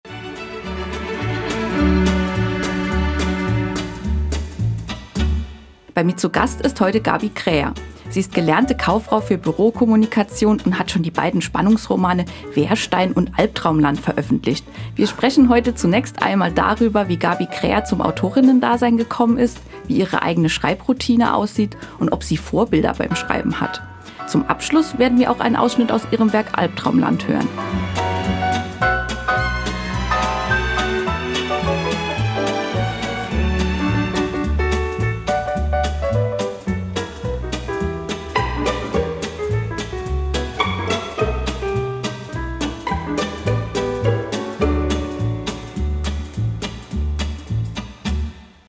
Beitrag
Moderation
Studiogast